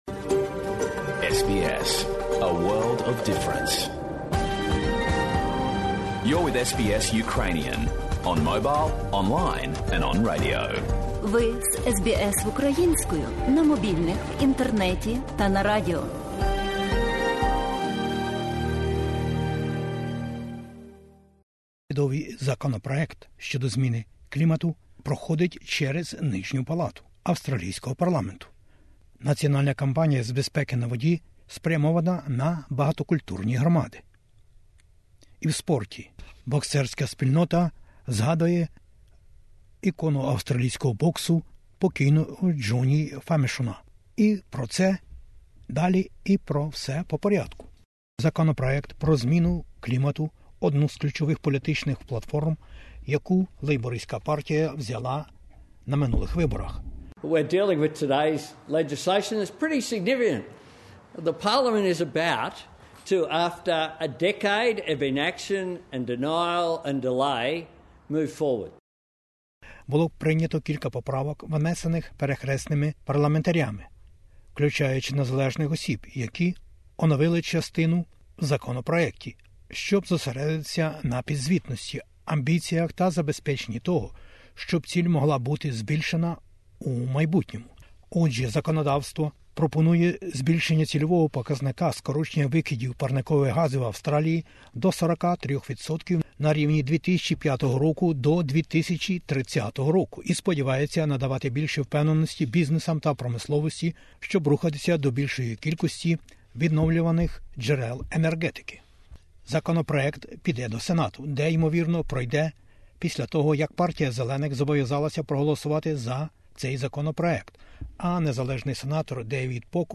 Бюлетень SBS новин українською мовою. Законопроєкт щодо зміни клімату пройшов перший етап у парламенті Австралії. Ящур - заходи щодо превентивності й безпеки.